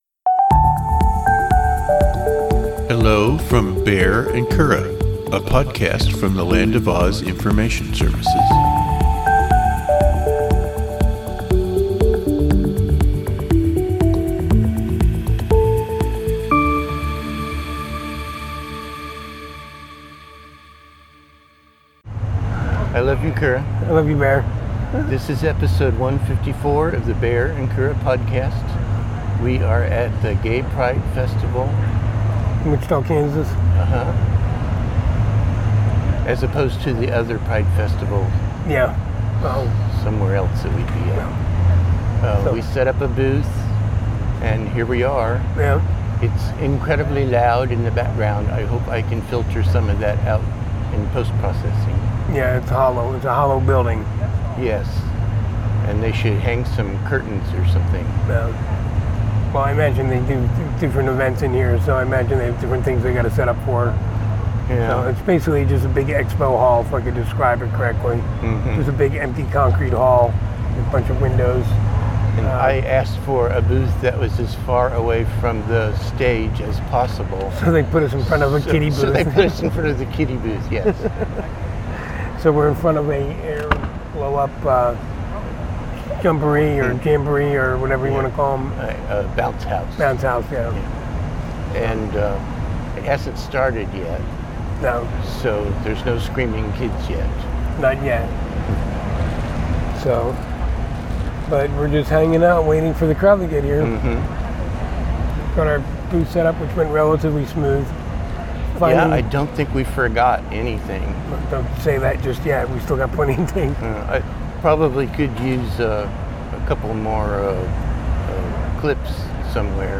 Two married gay guys discuss life, synergy, and the pursuit of happiness.
We are going to talk at the gay pride festival. This was recorded at our booth in the local gay pride festival. The sound will be different from the episodes we record at home.